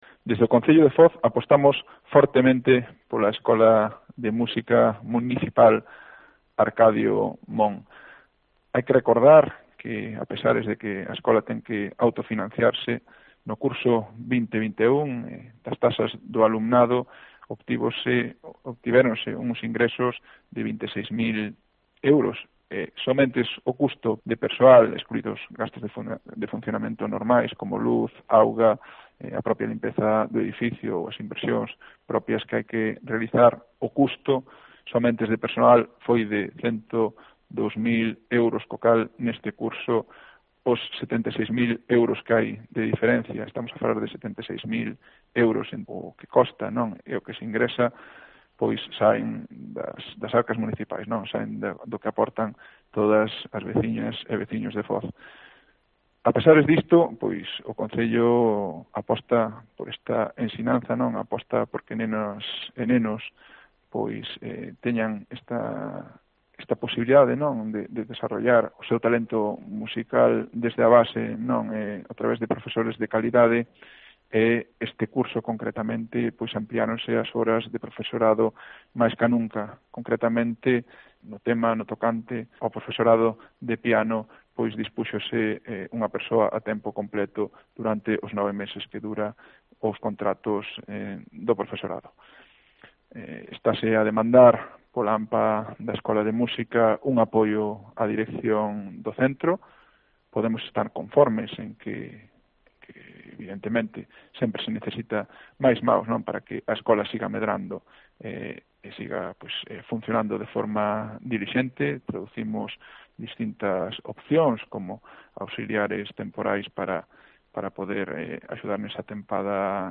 Declaraciones del alcalde de Foz sobre la Escuela Municipal de Música